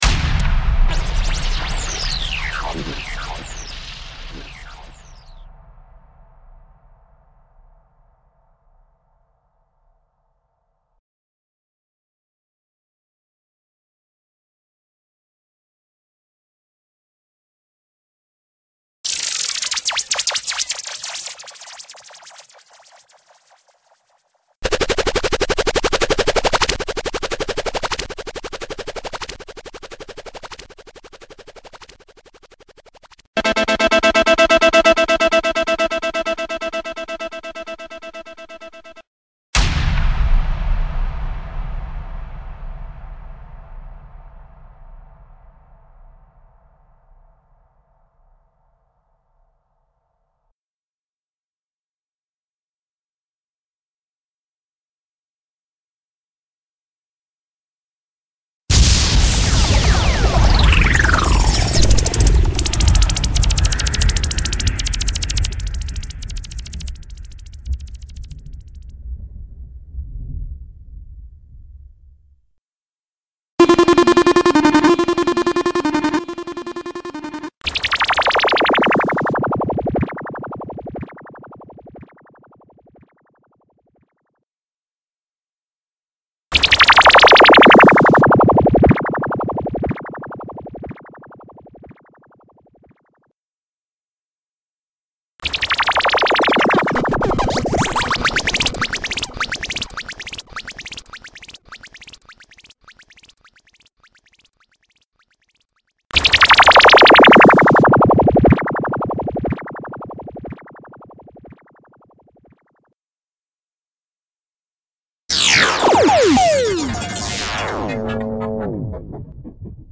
SOUND EFFECTS PACK 26
SFX-26.mp3